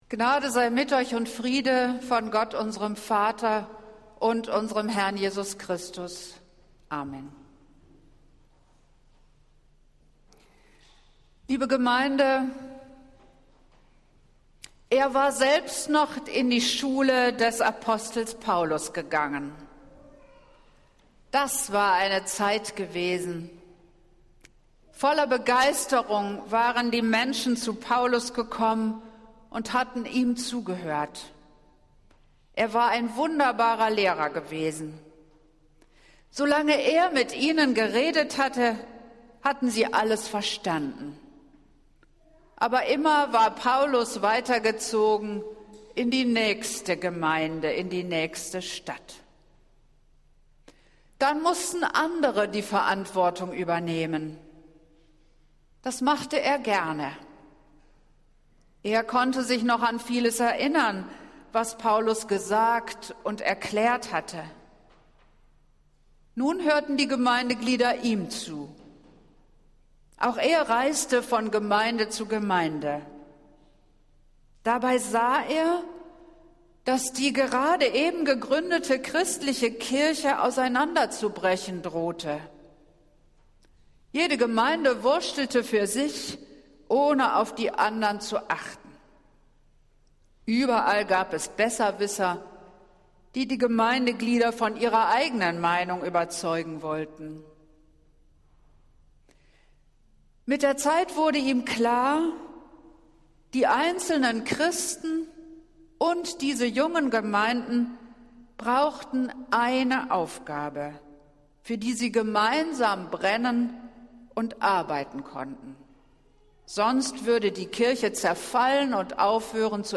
Predigt des Gottesdienstes aus der Zionskirche am Sonntag, den 16. Oktober 2022